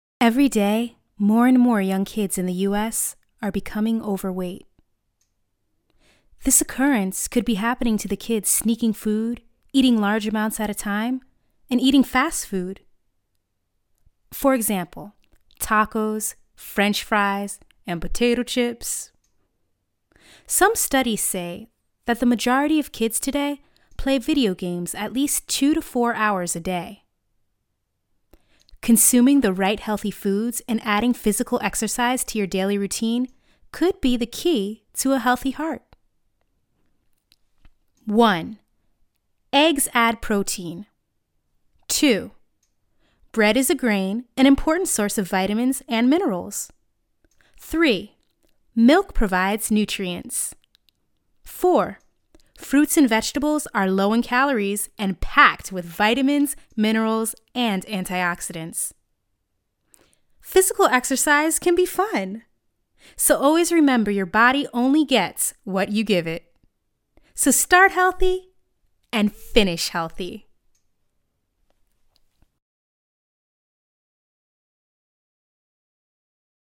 African American, Bilingual, Singer, Fun, Urban, Proper, Teen, Disney, Nickelodeon, Southern, Hip, Sassy, Sweet, Clear
Sprechprobe: eLearning (Muttersprache):
Naturally a Disney/Nickelodeon type of voice. HIGH ENERGY, young and FUN!!